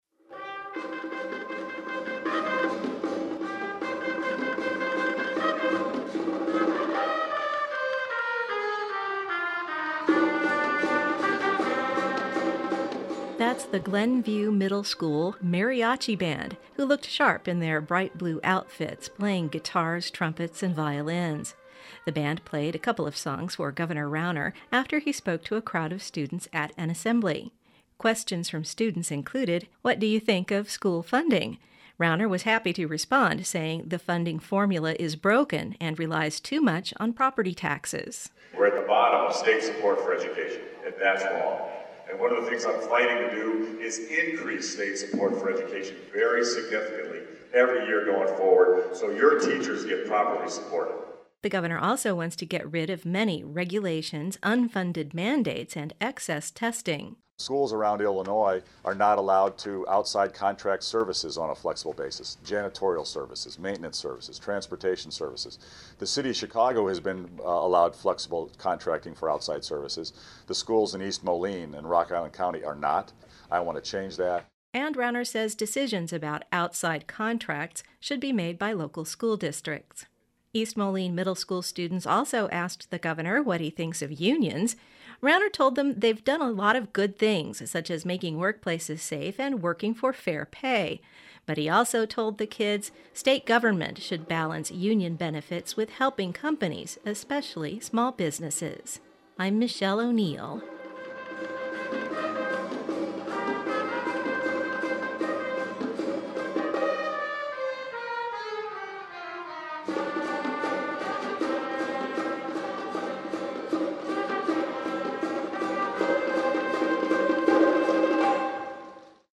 The Glenview Middle School Mariachi Band, dressed in bright blue outfits, playing guitars, trumpets, and violins, played a couple of songs for Gov. Rauner after he spoke to a crowd of students at an assembly.
Radio story featuring the school's Mariachi Band